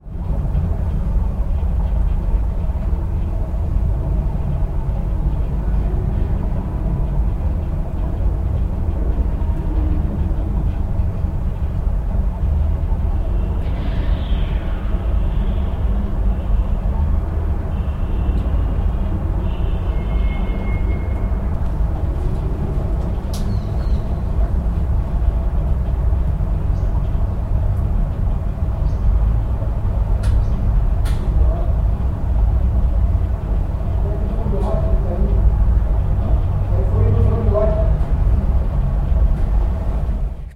Здесь собраны умиротворяющие аудиоэффекты: плеск воды, движение рыбок, пузырьки воздуха и другие природные звуки.
Атмосферные звуки океанариума